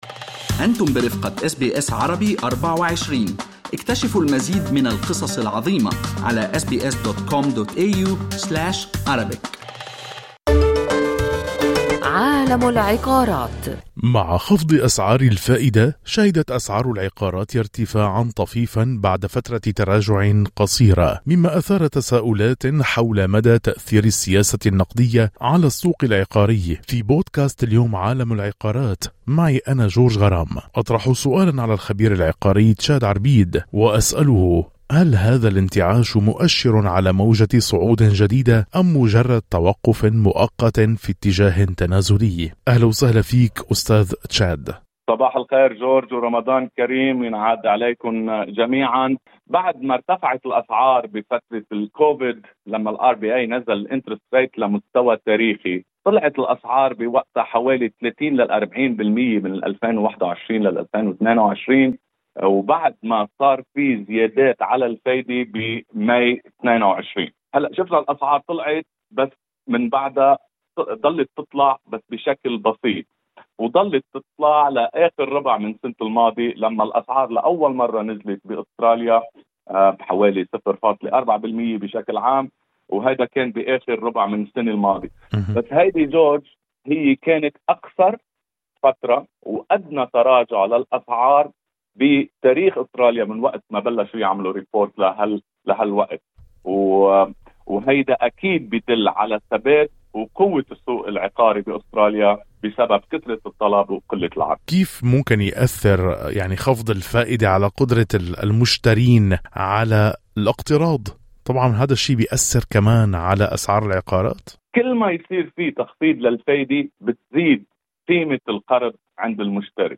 بينما يتساءل المستثمرون والمشترون المحتملون عما إذا كان الارتفاع الحالي في الأسعار يعكس انتعاشًا حقيقيًا أم مجرد توقف مؤقت في الاتجاه التنازلي سلط بودكاست "عالم العقارات" الضوء على هذه القضية في حوار معمّق مع الخبير العقاري